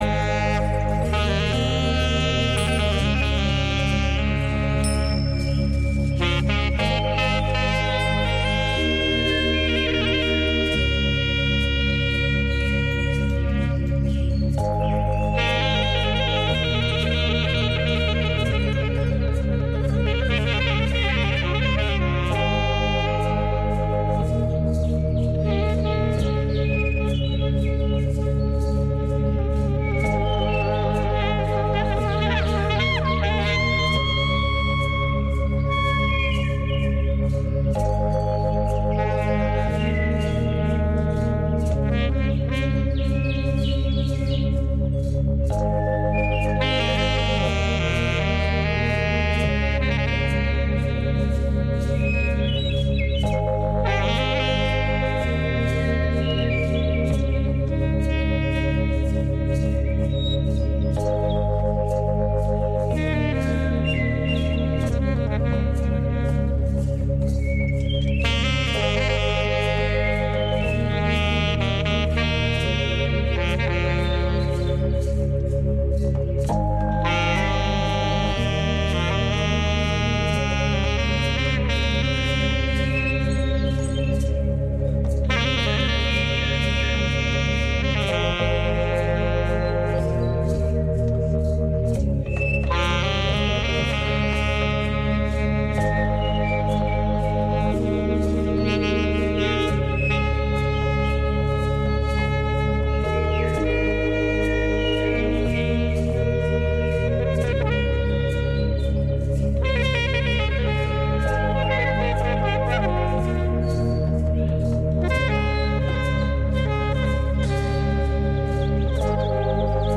Our apologies for the technical difficulties in this one